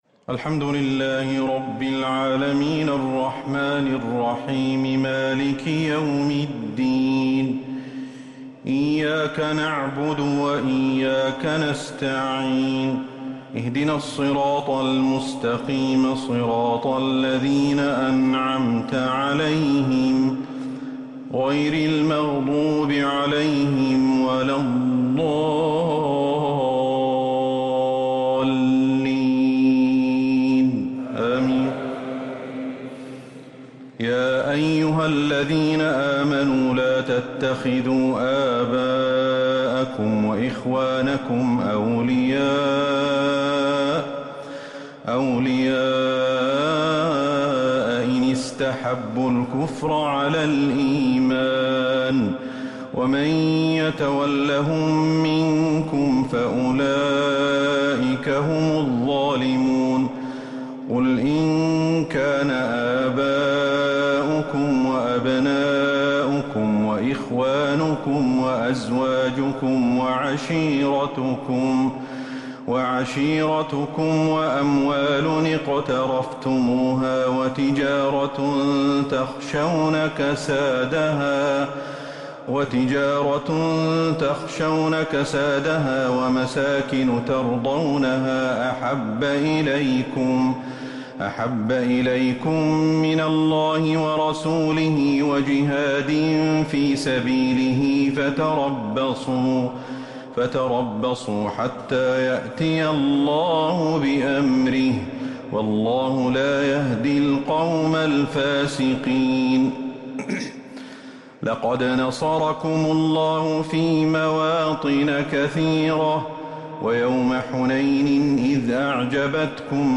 تراويح ليلة 13 رمضان 1444هـ من سورة التوبة (23-60) | taraweeh 13st niqht Surah At-Tawba 1444H > رمضان 1444هـ > التراويح - تلاوات الشيخ أحمد الحذيفي